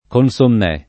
konSomm$+] o consumè [konSum$+], oppure calcato in consumato [konSum#to]